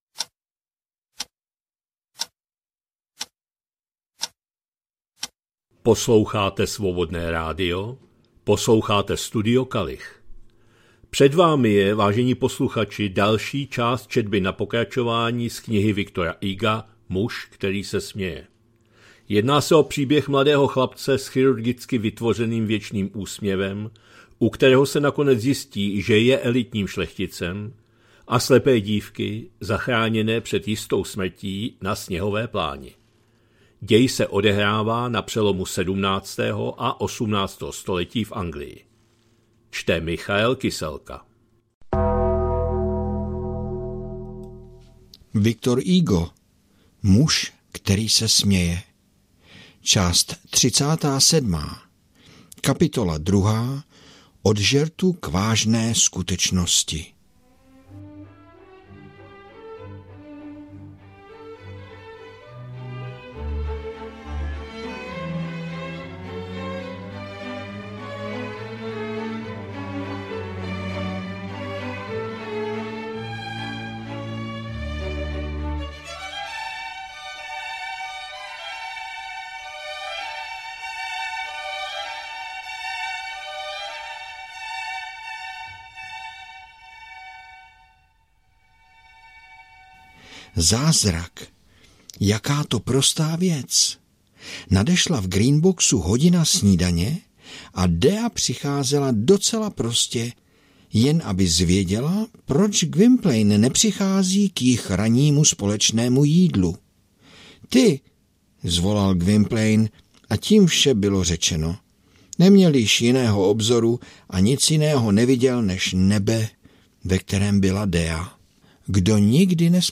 2025-11-28 – Studio Kalich – Muž který se směje, V. Hugo, část 37., četba na pokračování